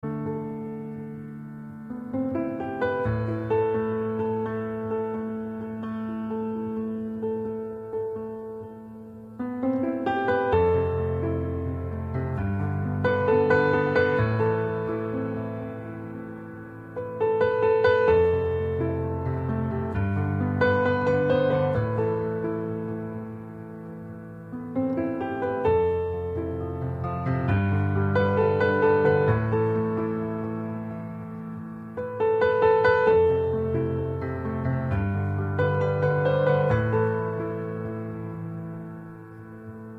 без слов
пианино